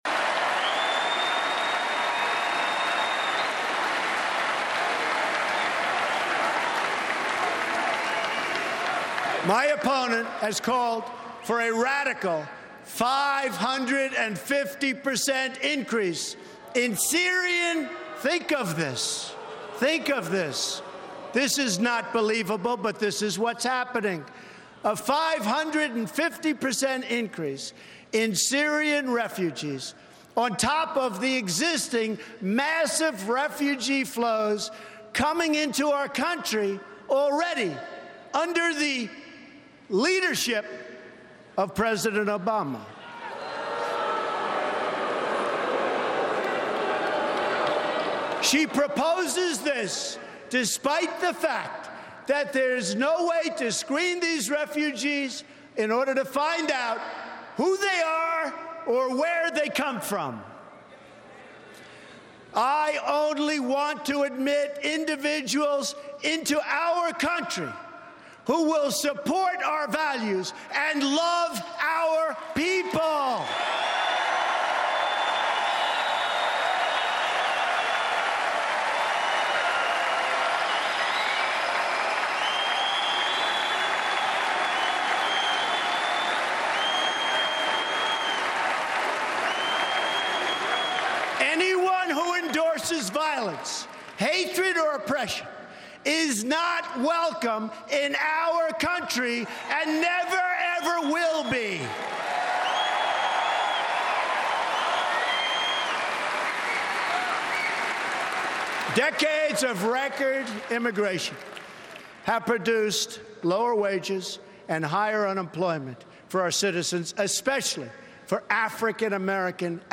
Then listeners call in to share their thoughts on the speech.